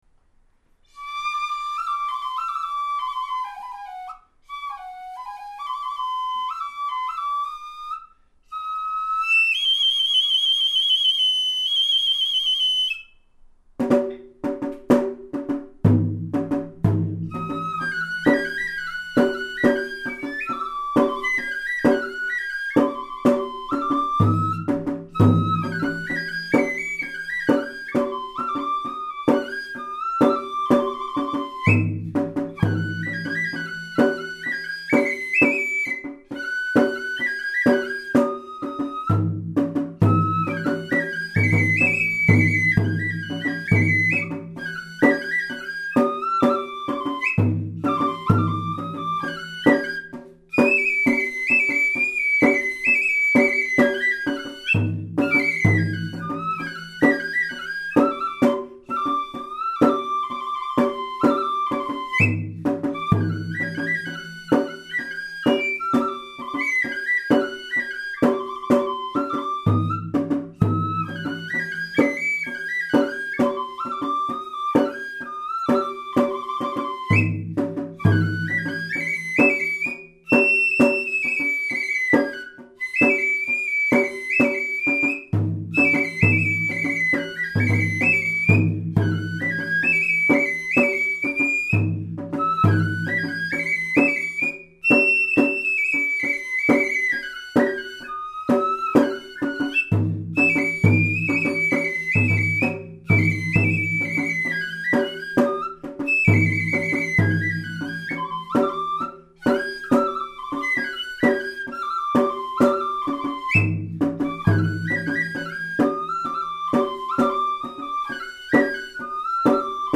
なお、笛の方はとっても上手ですが、太鼓（実は大部分が私）は数箇所間違っております。
吹き出しの高音や太鼓の入りは、知立でよく使われているパターンでやっています。